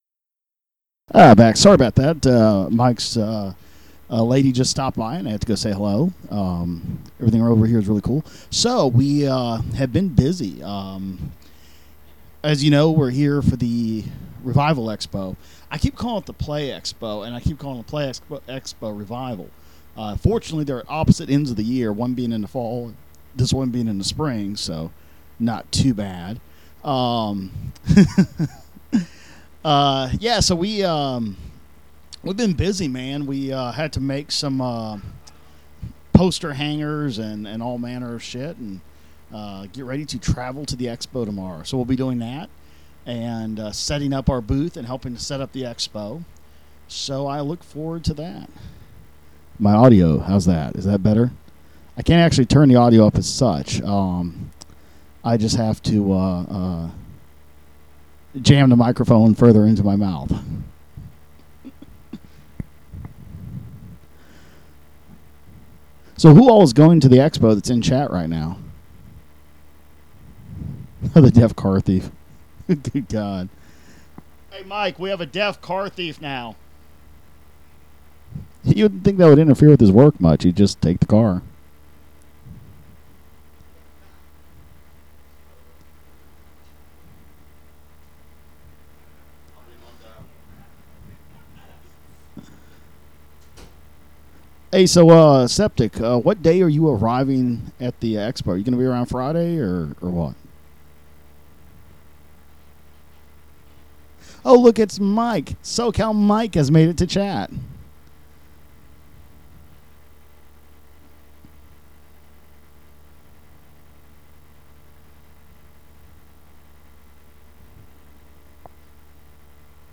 LIVE NEWS PRE SHOW
Unedited